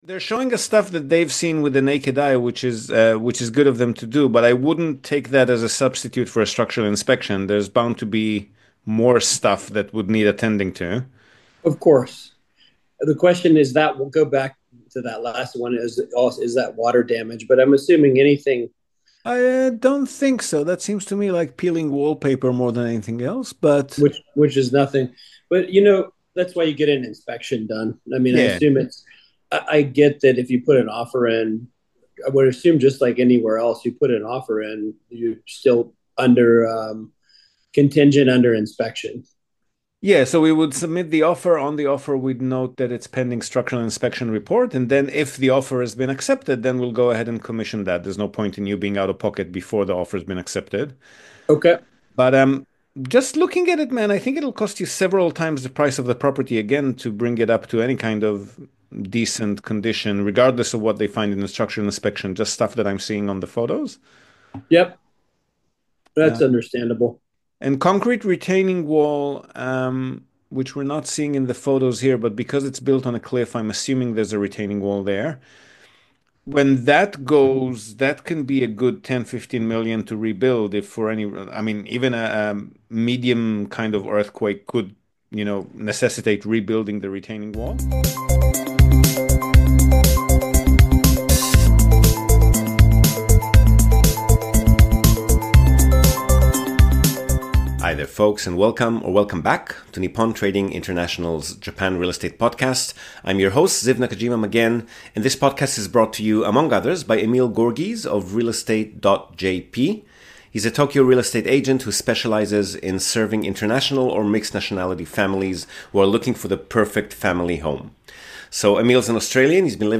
The Investing Power Hour is live-streamed every Thursday on the Chit Chat Stocks Podcast YouTube channel at 5:00 PM EST.